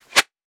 weapon_bullet_flyby_05.wav